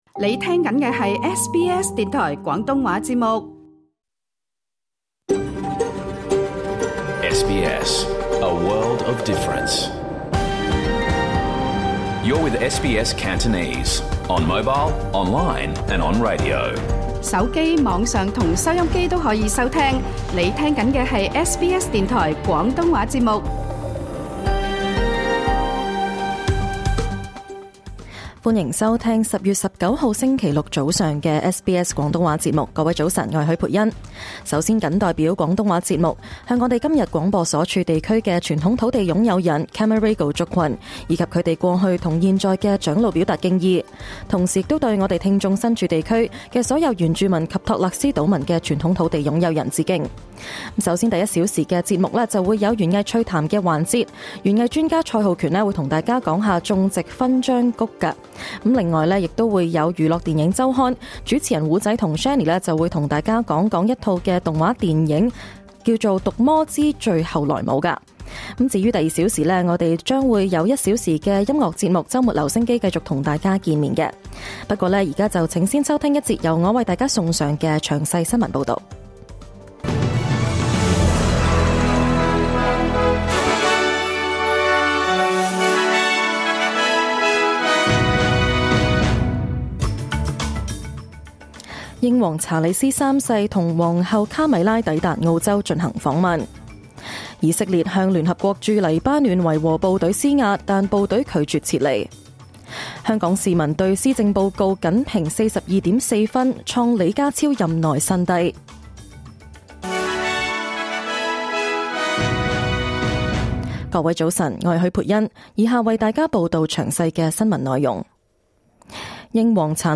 2024 年 10 月 19 日 SBS 廣東話節目詳盡早晨新聞報道。